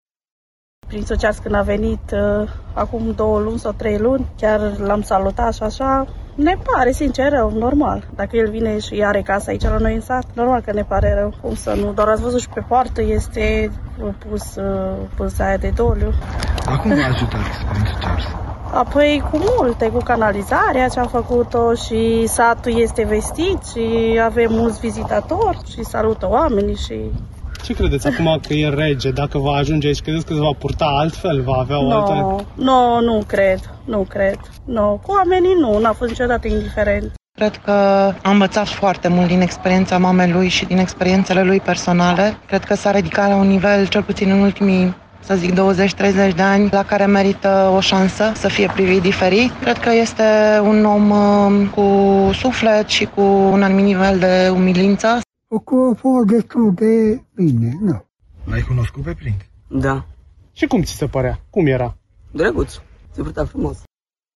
vox-viscri.mp3